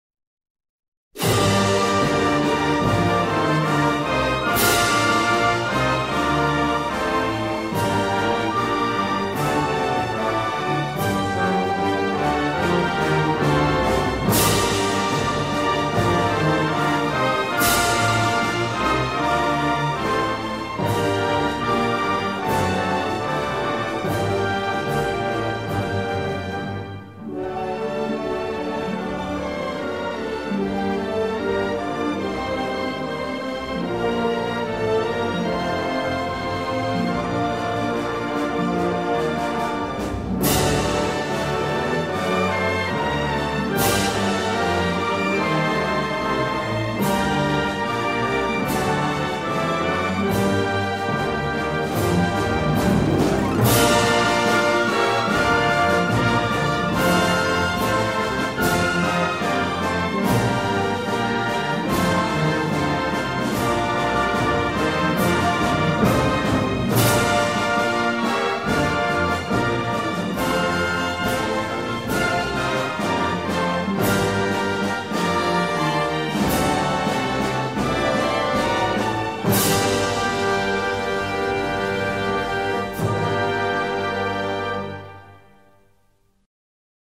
• Качество: высокое
Торжественная мелодия и слова патриотической песни \